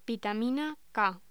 Locución: Vitamina K
voz